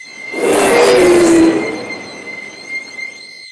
launch_pri_projectile.wav